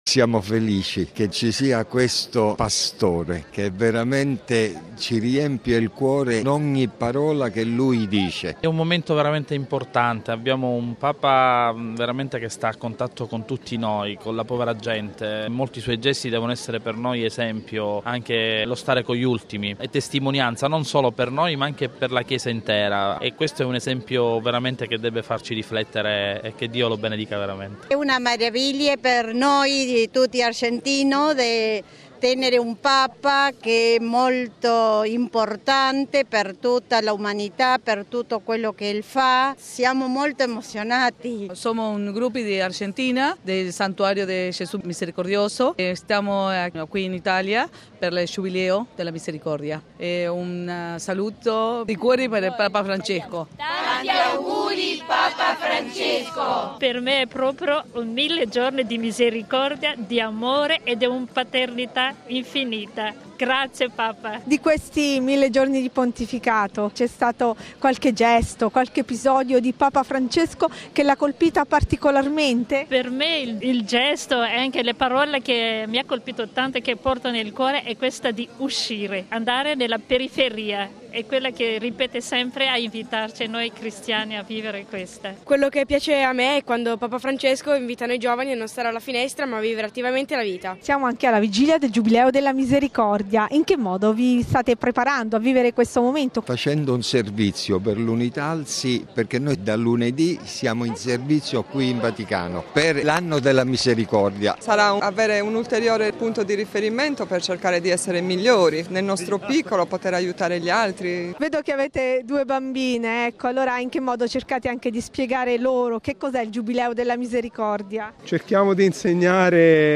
Decine di migliaia di fedeli erano presenti questa mattina in Piazza San Pietro per ascoltare l’Angelus del Papa e anche per festeggiare in anticipo i primi 1000 giorni del suo Pontificato che ricorrono proprio l'8 dicembre, inizio del Giubileo della Misericordia.